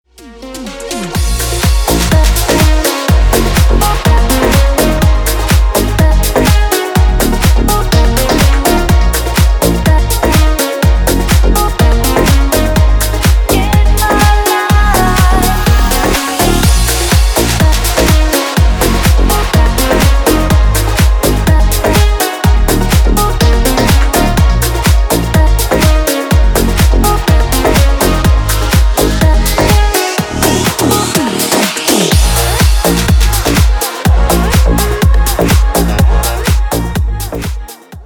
• Качество: 320, Stereo
deep house
женский голос
dance
Electronic
nu disco